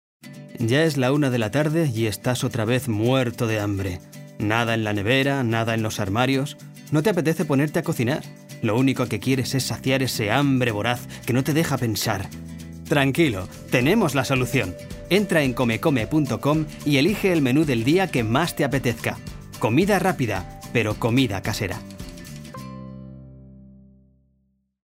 Tengo una voz joven, educada y aplicable en multitud de estilos, como publicidad, documentales, audiolibros,... Especial habilidad para interpretar personajes con voz fuera de lo común. Amplia experiencia en "listenings" de cursos de español.
kastilisch
Sprechprobe: Werbung (Muttersprache):